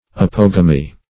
Apogamy \A*pog"a*my\, n. [Pref. apo- + Gr.